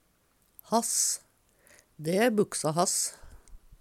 hass - Numedalsmål (en-US)